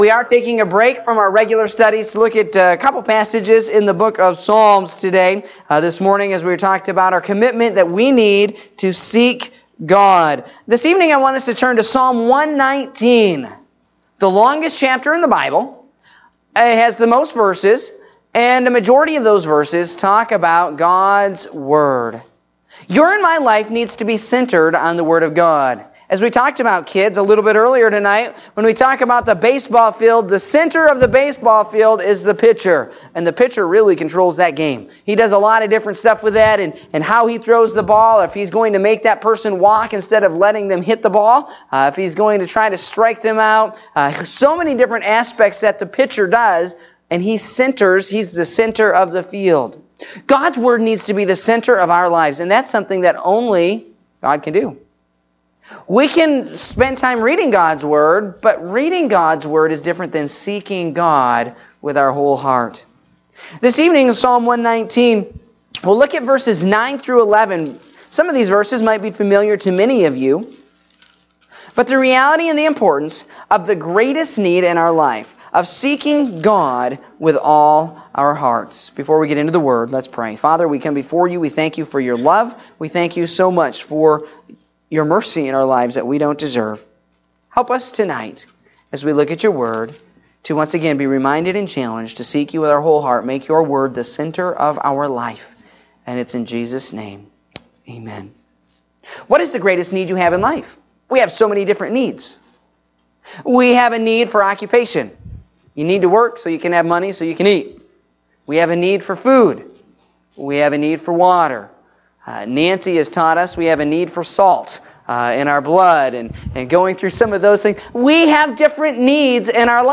Evening Service (07/23/2017)